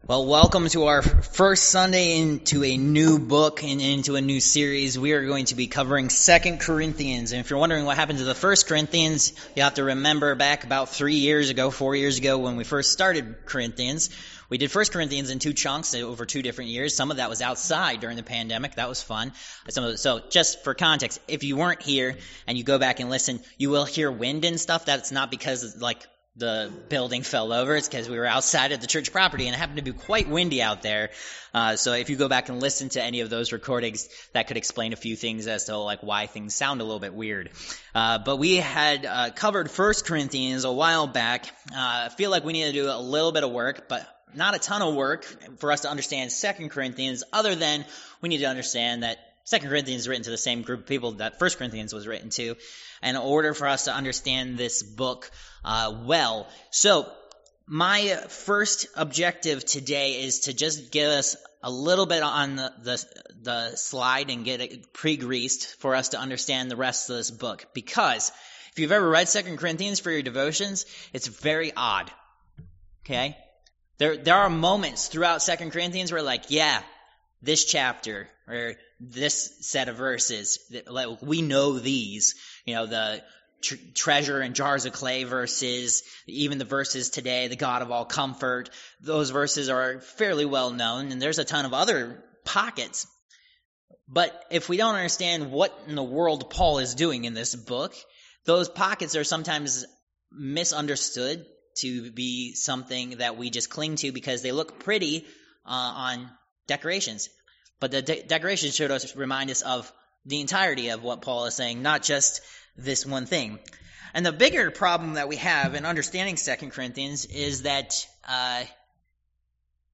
2 Cor. 1:1-11 Service Type: Worship Service « Protected